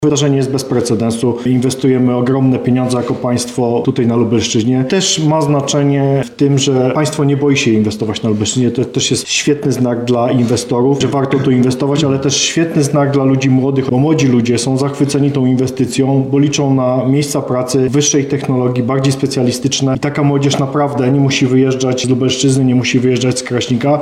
– mówi Wicewojewoda Lubelski Andrzej Maj.